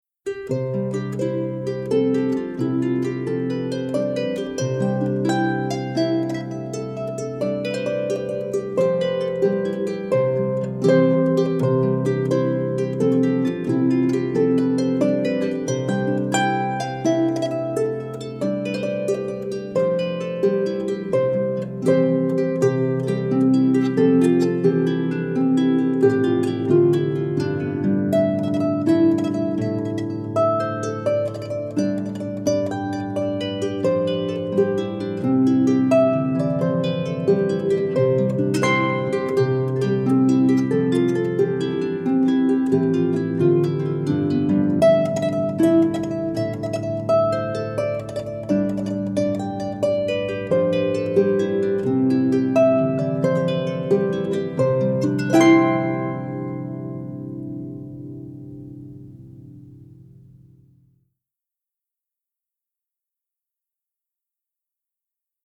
is for solo lever or pedal harp